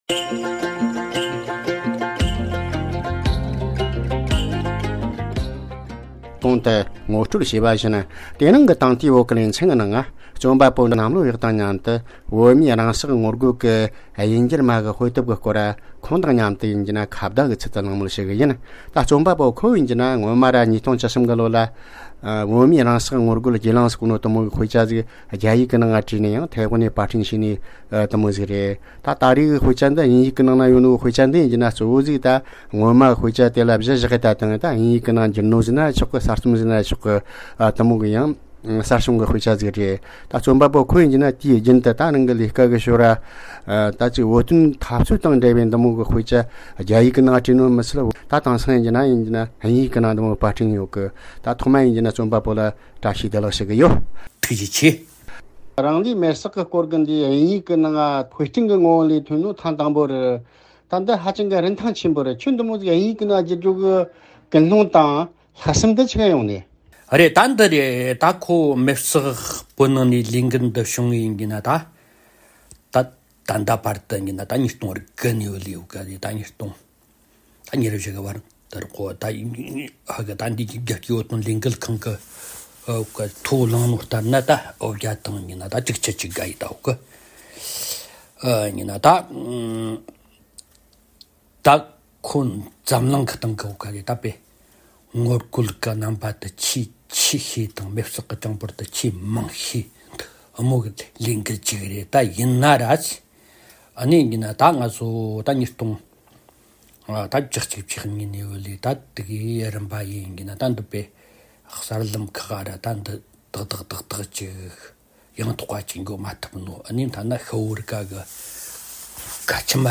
སྒྲ་ལྡན་གསར་འགྱུར། སྒྲ་ཕབ་ལེན།
བཅར་འདྲི